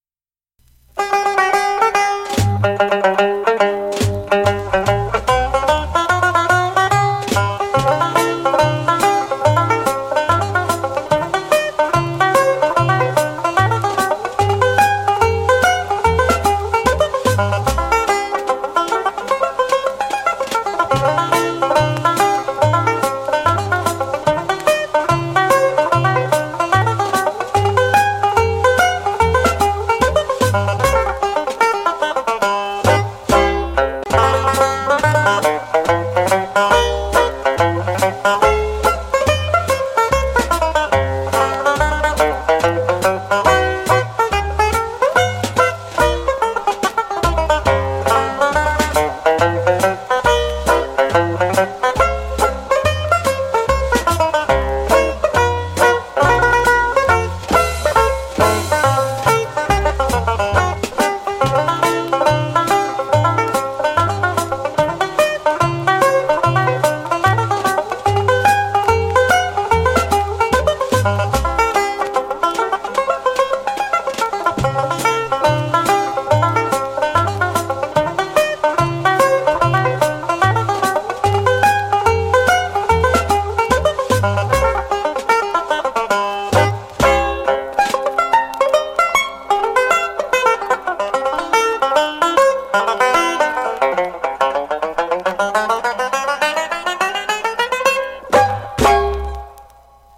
plectrum banjo